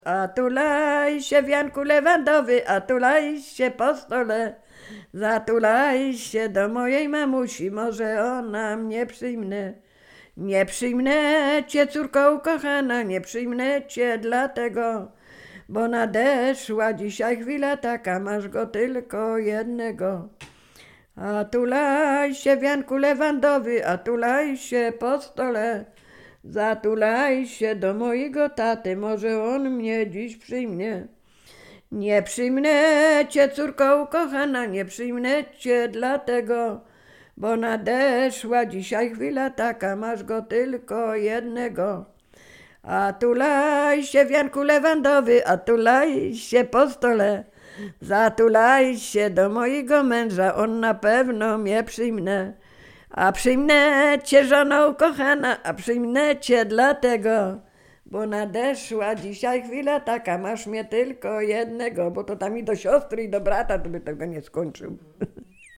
Sieradzkie
Weselna
wesele wianek oczepinowe